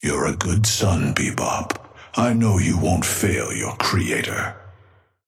Amber Hand voice line - You're a good son, Bebop. I know you won't fail your creator.
Patron_male_ally_bebop_start_04.mp3